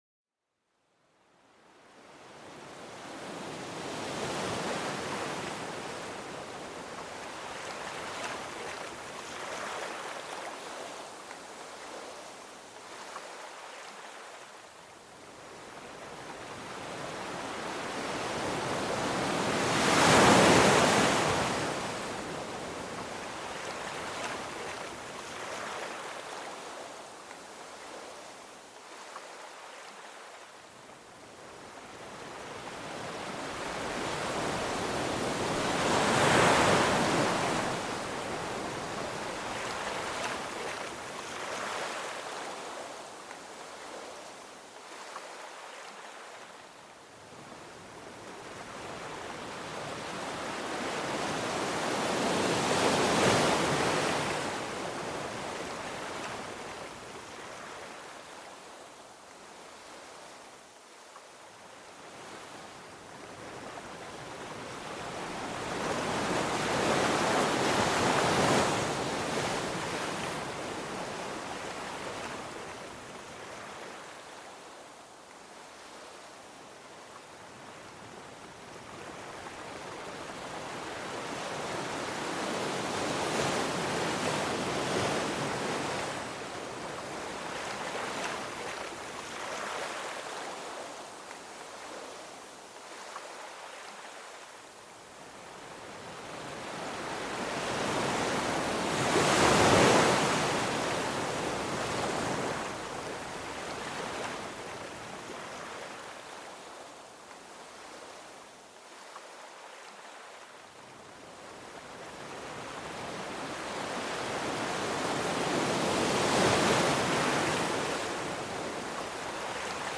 Écoutez le rythme apaisant des vagues, une mélodie qui se renouvelle sans cesse au bord de l'océan.Plongez dans une tranquillité profonde, où le souffle de la mer berce vos pensées vers une paix intérieure.Laissez le son des vagues vous guider à travers un voyage de relaxation et de connexion avec la nature.Ce podcast est une expérience audio immersive qui plonge les auditeurs dans les merveilleux sons de la nature.